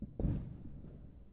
描述：单个烟花爆炸在Santa Ana内的各个地方爆炸，与Roland CS10EM双耳麦克风/耳机和Zoom H4n Pro一起记录。没有添加后处理。
标签： 双耳 响亮 fieldrecord 火箭 烟火 烟花爆竹 爆炸 fieldrecording 第四的 - 7月份 鞭炮 fieldrecording 爆炸 烟花 爆竹 NEWYEAR 繁荣 爆炸 newyears binauralrecording 易爆 KABOOM 环境 火箭 炸弹 binaur
声道立体声